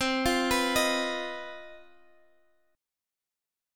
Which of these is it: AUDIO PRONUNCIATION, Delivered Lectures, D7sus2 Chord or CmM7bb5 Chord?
CmM7bb5 Chord